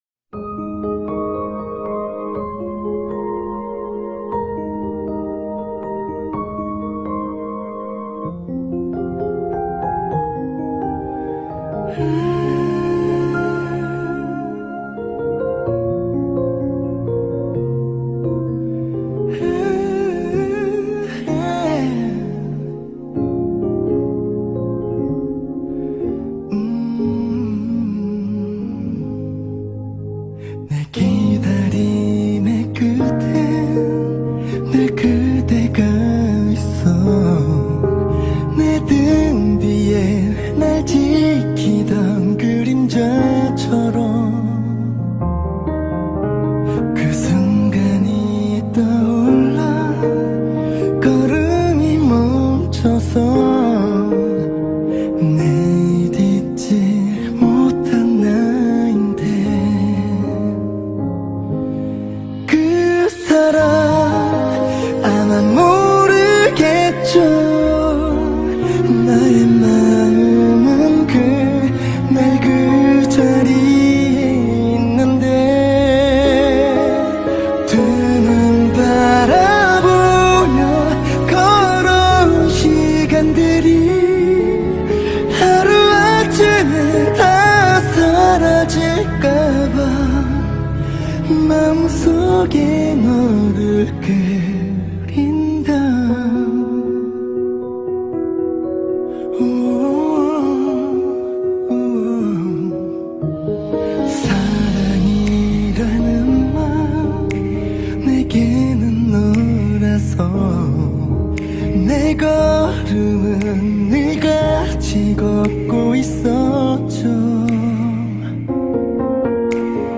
آهنگ کره ای